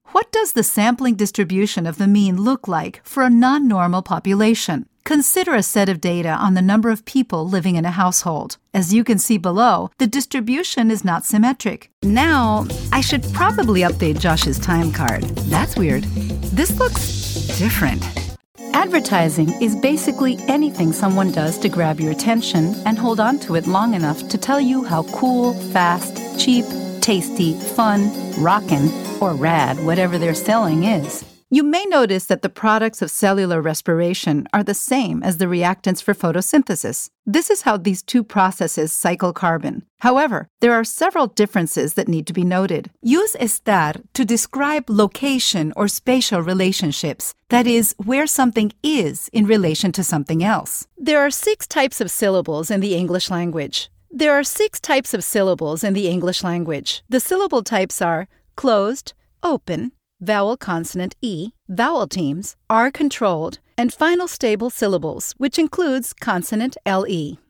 E-learning
Mis clientes describen mejor mi voz como amigable, chispeante, expresiva, agradable, cálida y entusiasta.